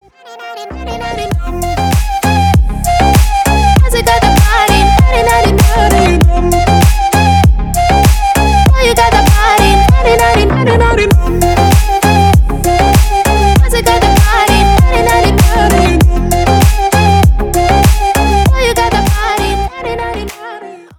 • Качество: 320, Stereo
Dance Pop
забавный голос
house
club mix
europop
танцевальная музыка